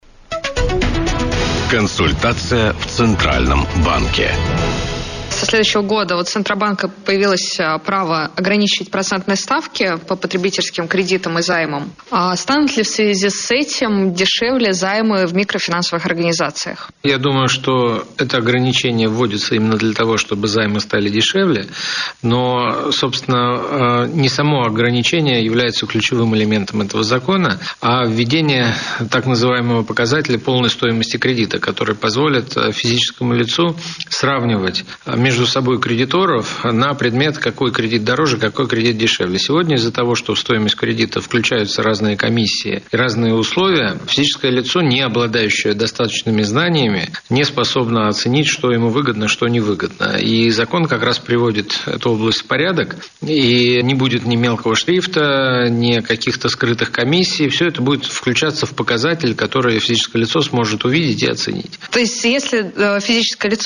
Интервью
Интервью первого заместителя Председателя Банка России — руководителя Службы Банка России по финансовым рынкам С.А. Швецова радиостанции «Бизнес ФМ» 19 декабря 2013 года
interview_shvetsov_audio.mp3